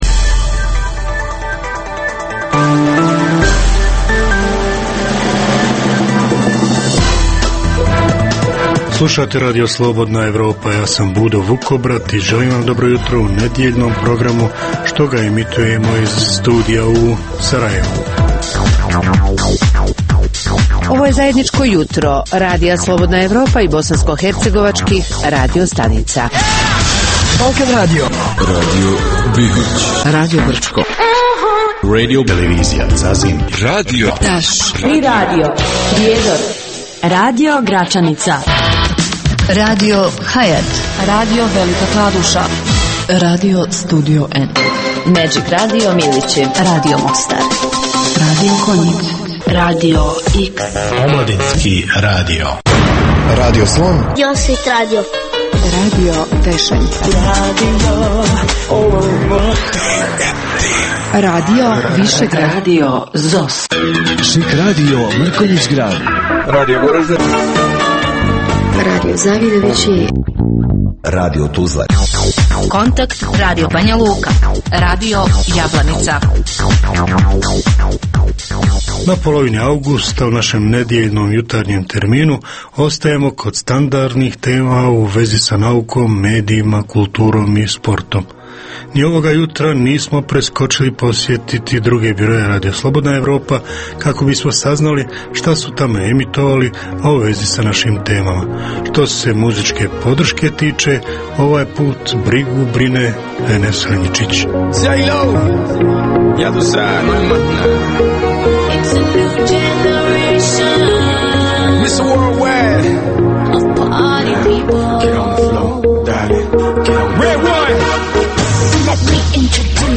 Jutarnji program namijenjen slušaocima u Bosni i Hercegovini. Uz vijesti i muziku, poslušajte pregled novosti iz nauke i tehnike, te čujte šta su nam pripremili novinari RSE iz regiona.